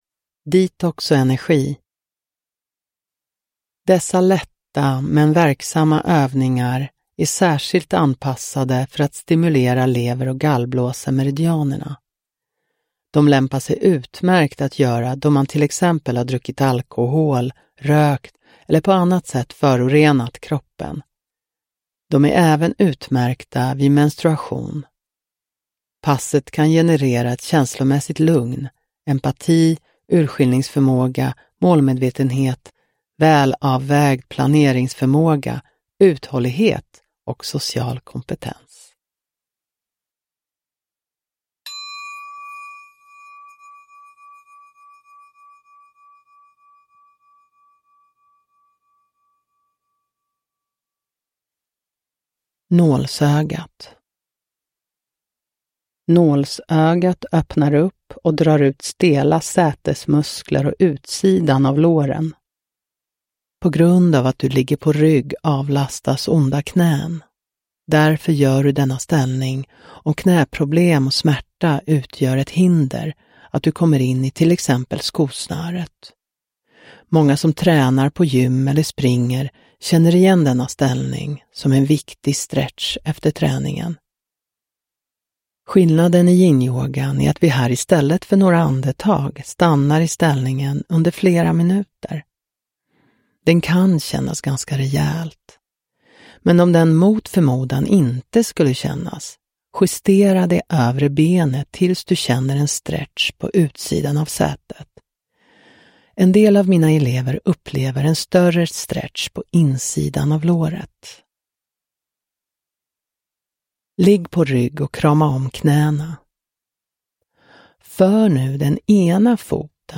Detox och energi – Ljudbok – Laddas ner